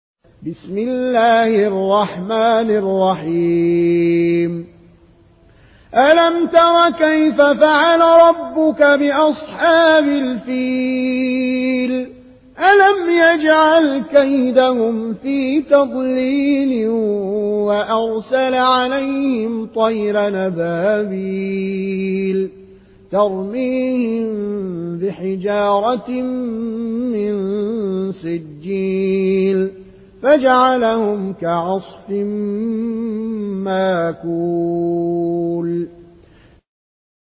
(رواية ورش)